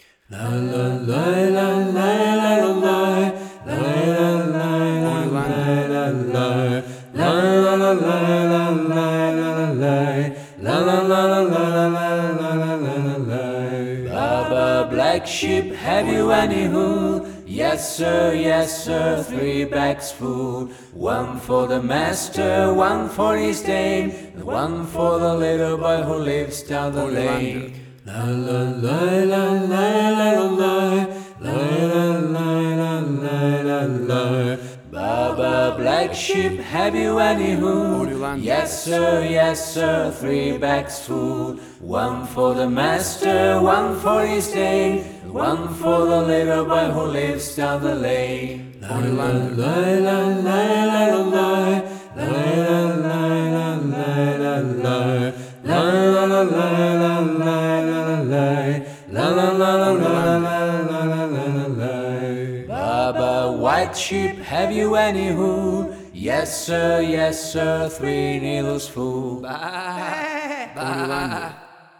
Tempo (BPM): 70